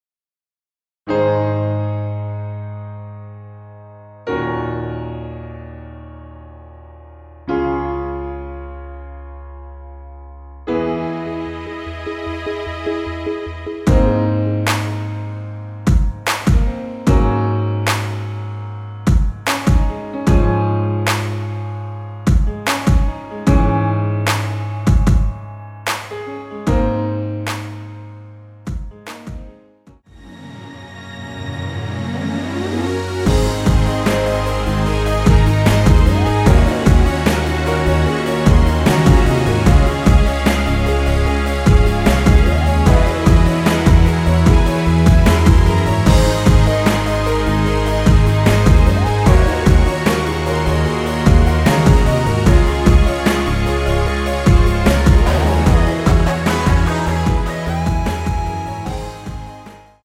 Ab
음정은 반음정씩 변하게 되며 노래방도 마찬가지로 반음정씩 변하게 됩니다.
앞부분30초, 뒷부분30초씩 편집해서 올려 드리고 있습니다.